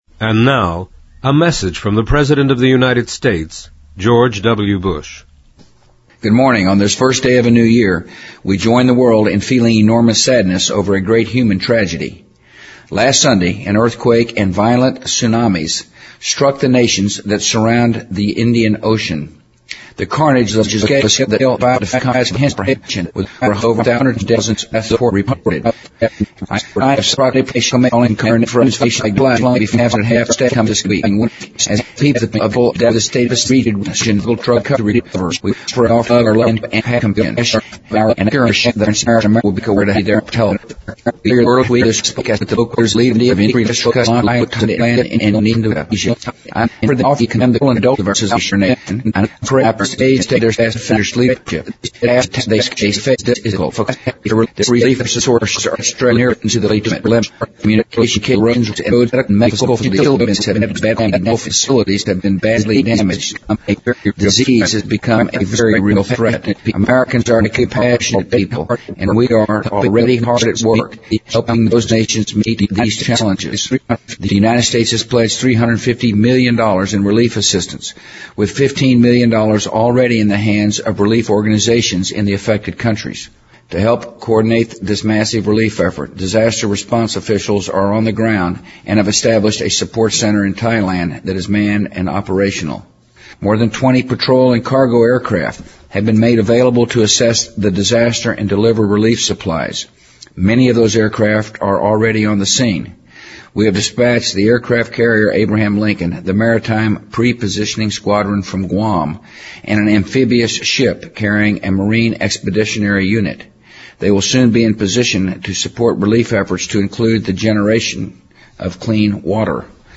President Bush-2005-01-01电台演说 听力文件下载—在线英语听力室